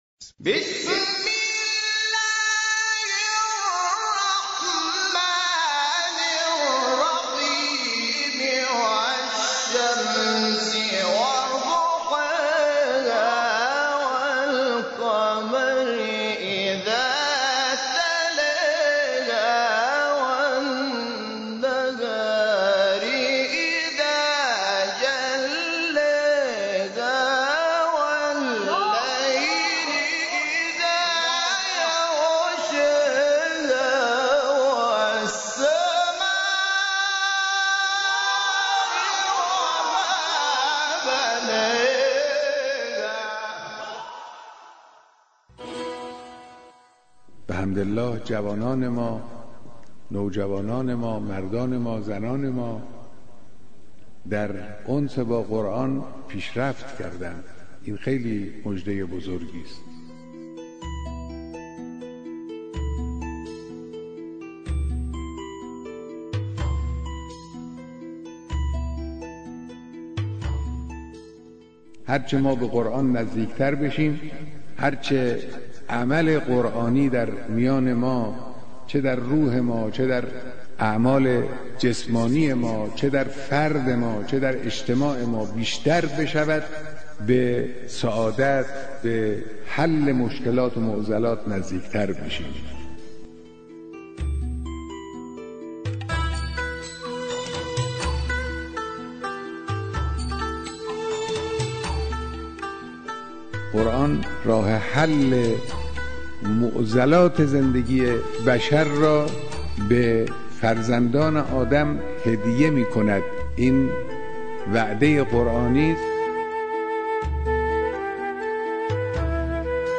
زندگی قرانی (سخنان امام خامنه ای)